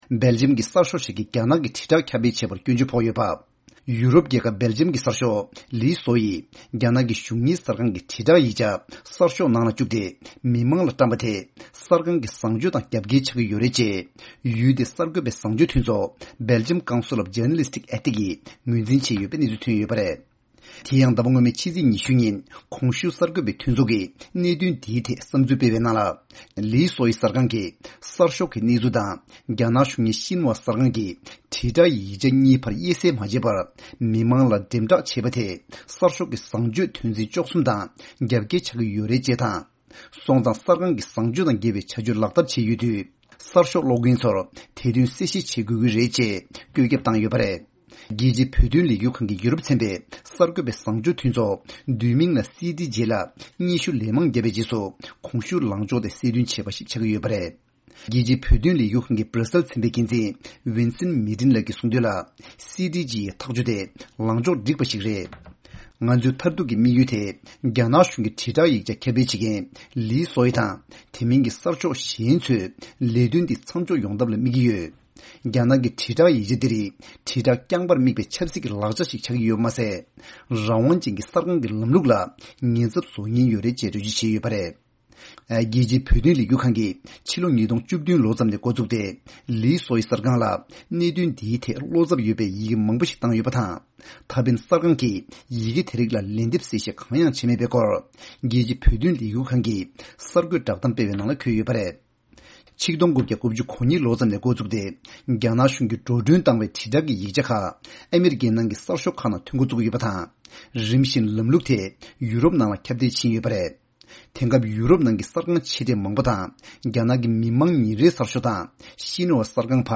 གནས་ཚུལ་སྙན་སྒྲོན་ཞུ་ཡི་རེད།།